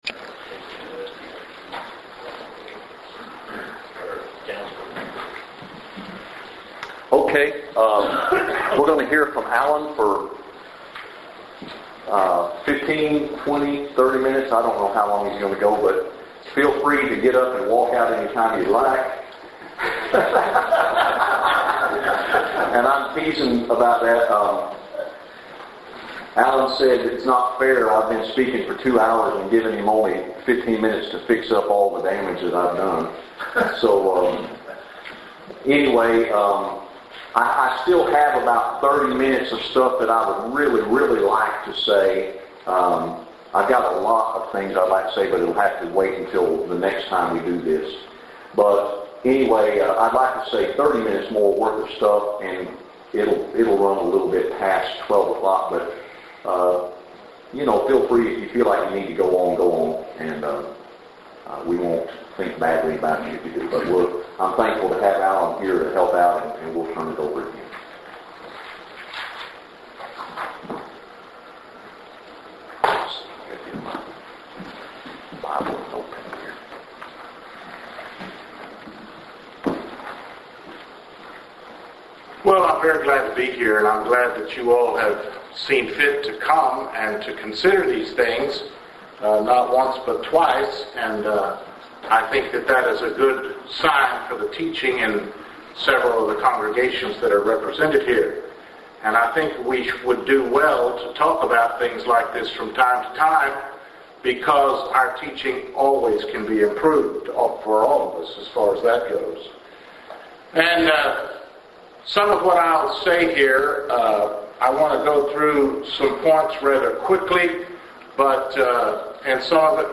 Preacher/Teacher Training